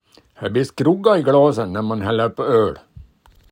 Så här kan skroggâ användas i en mening